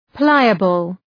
Προφορά
{‘plaıəbəl}